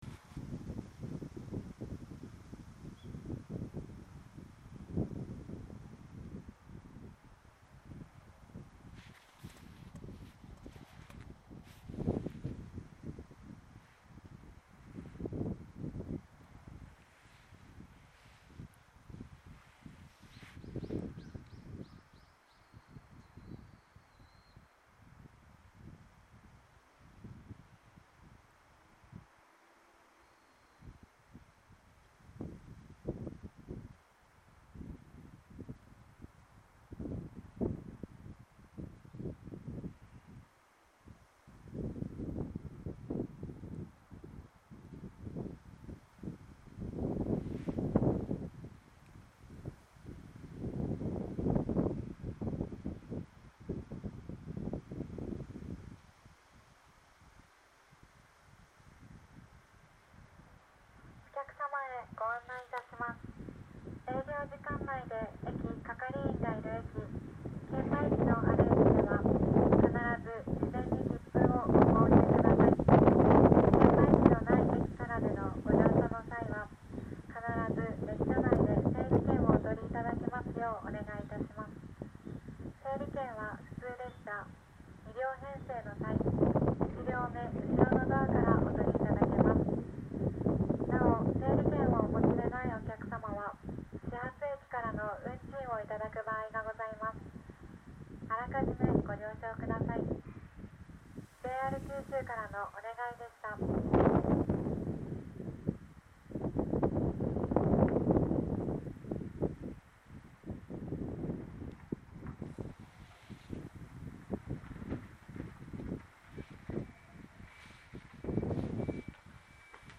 この駅では接近放送が設置されています。
１番のりば日豊本線
接近放送普通　西都城行き接近放送です。